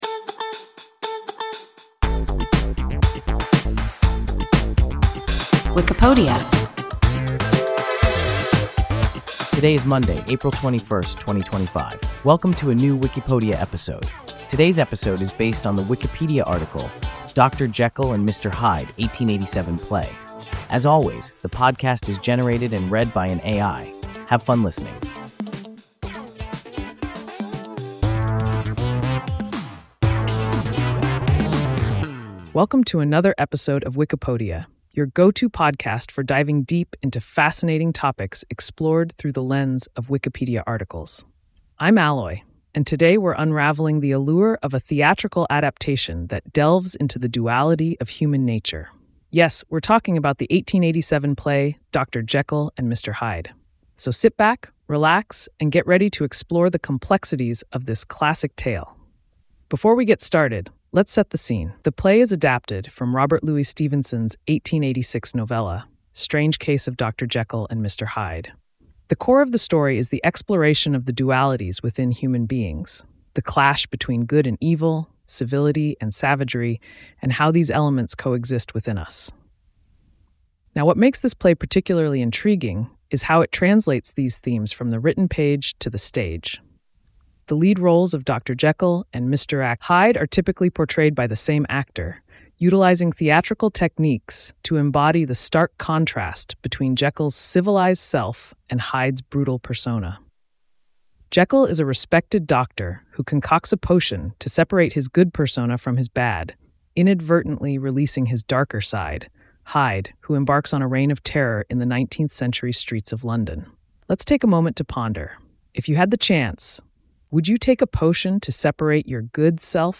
Dr. Jekyll and Mr. Hyde (1887 play) – WIKIPODIA – ein KI Podcast